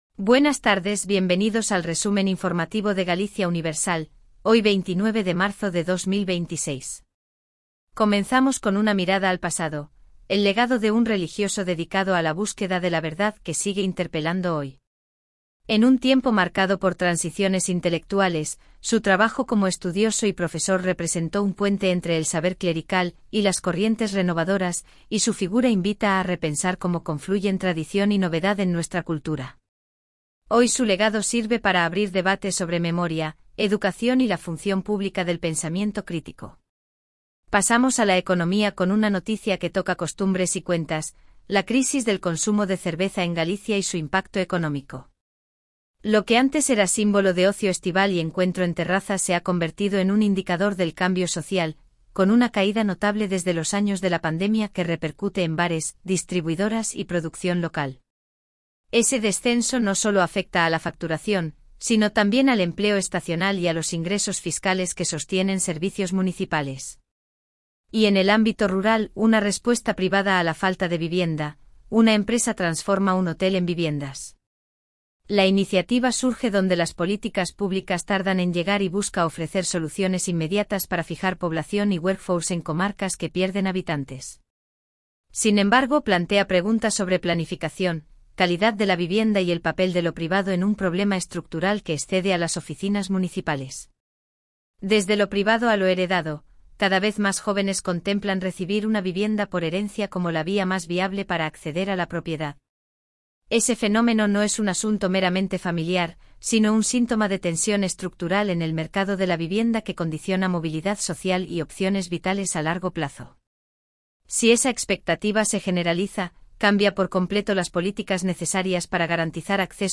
🎙 PODCAST DIARIO
Resumen informativo de Galicia Universal